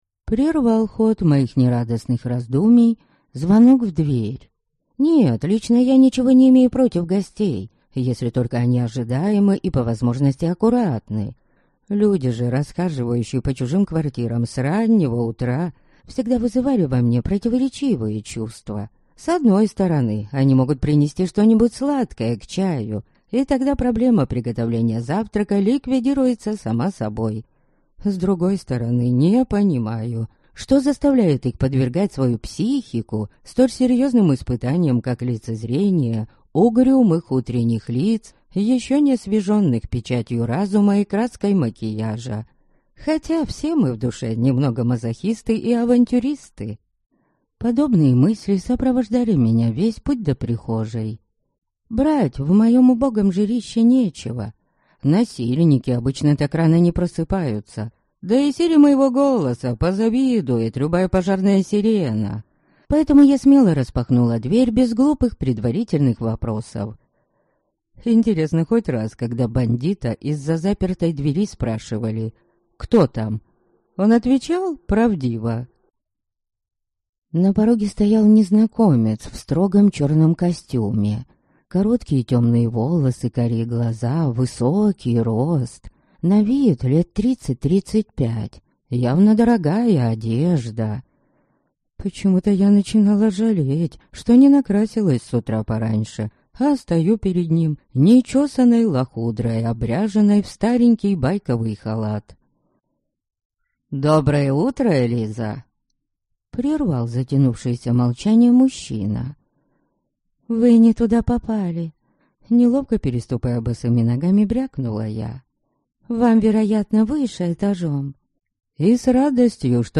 Аудиокнига Летопись безумных дней | Библиотека аудиокниг